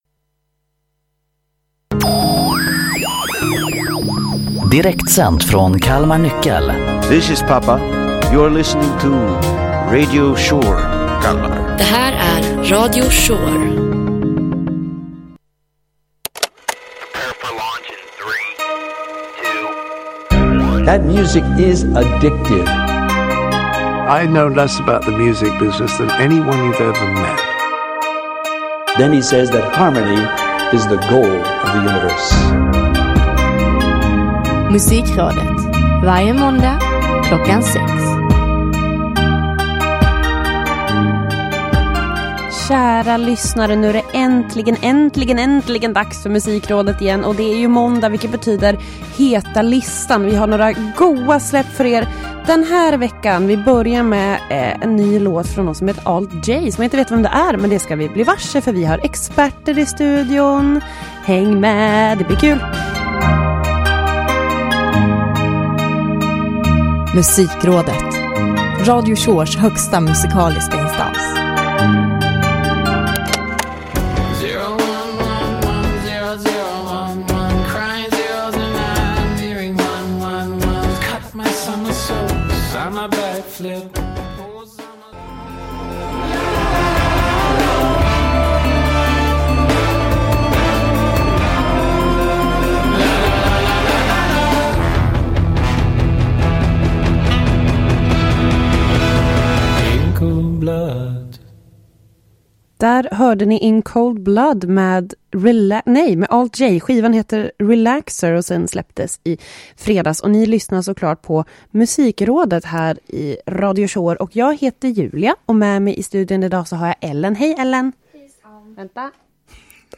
Musikredaktionen AKA Radio Shores högsta musikaliska instans presenterar Heta Listan varje måndag. Bra och purfärsk musik kombineras med starka åsikter och vilda spekulationer.